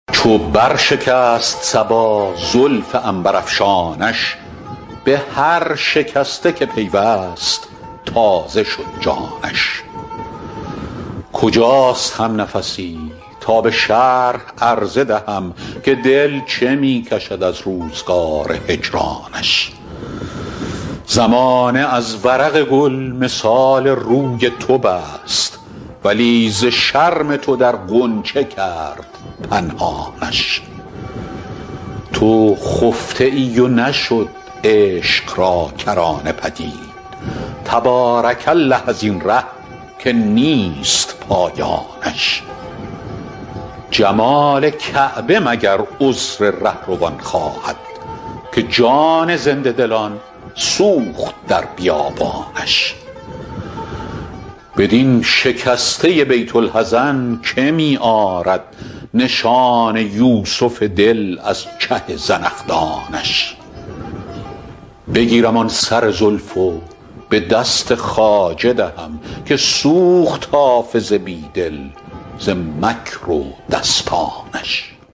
حافظ غزلیات غزل شمارهٔ ۲۸۰ به خوانش فریدون فرح‌اندوز